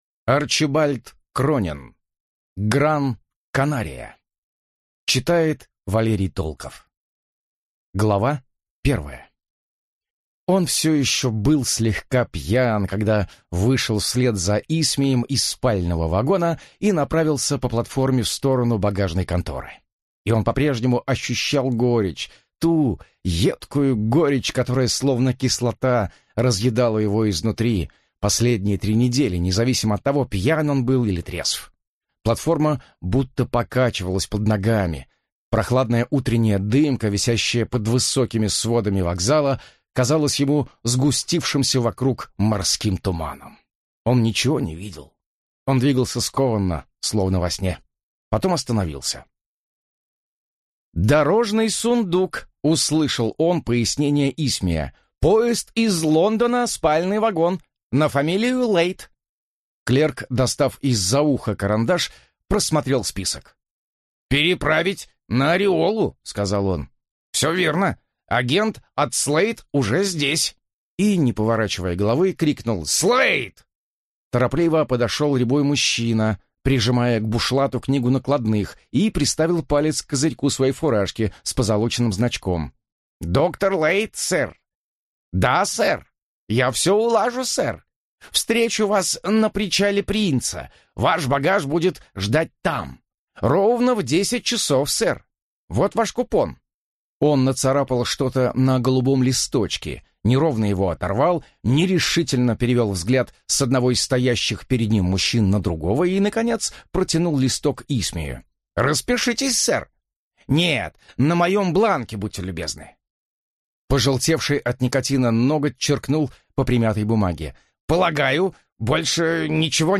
Аудиокнига Гран-Канария | Библиотека аудиокниг